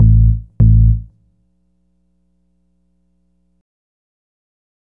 GrooveBass 11-44S.wav